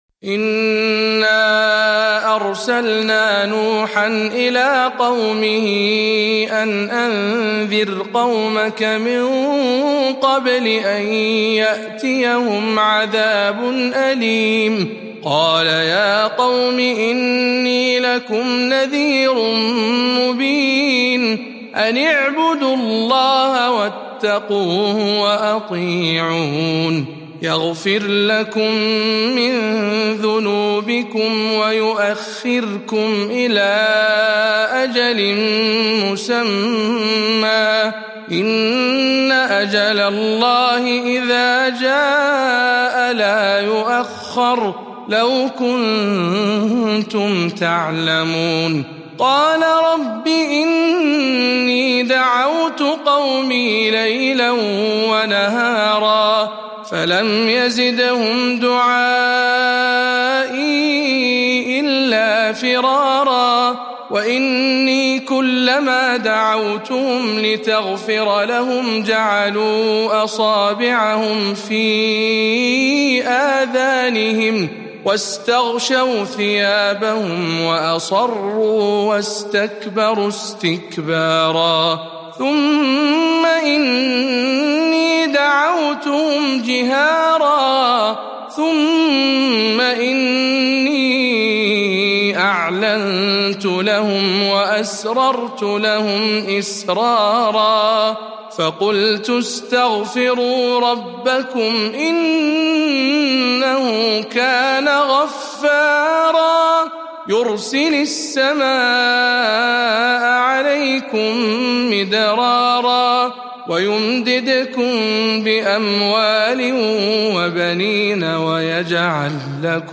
سورة نوح برواية شعبة عن عاصم